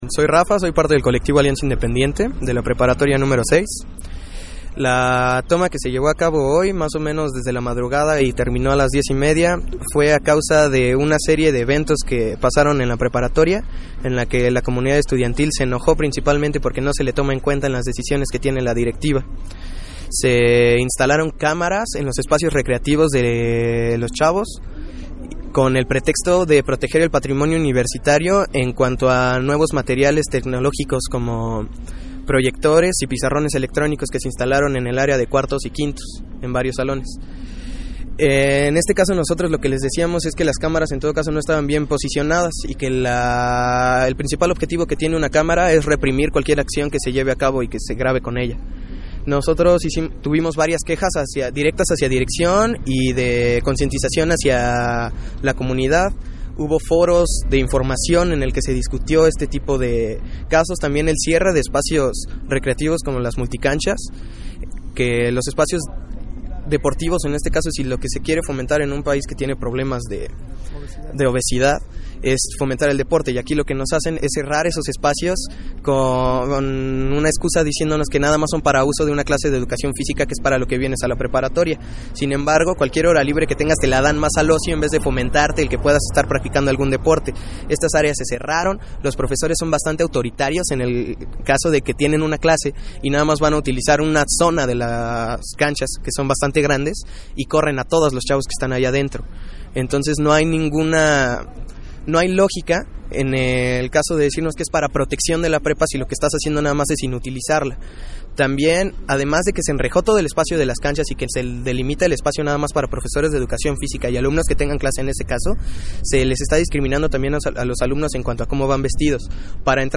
Además Regeneración Radio realizó una entrevista con uno de los estudiantes, en donde explica las razones de la toma de las instalaciones.